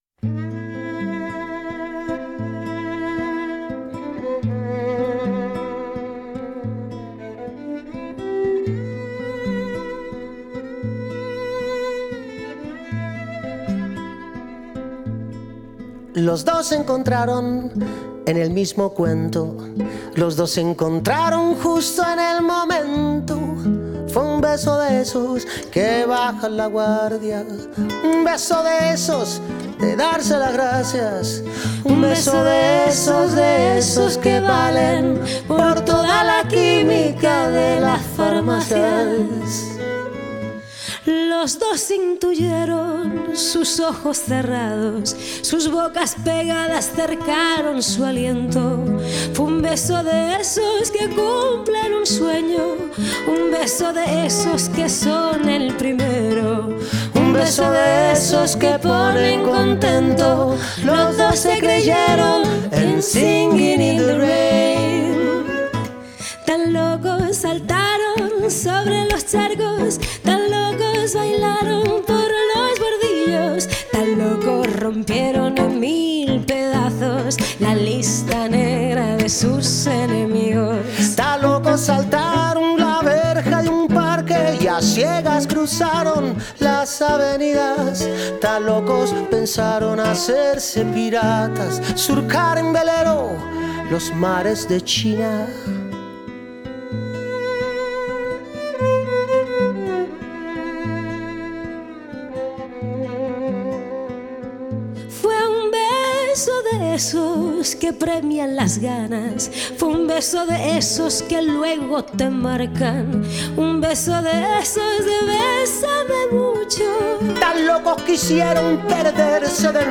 un disco de duetos